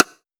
Urban Rimshot 01.wav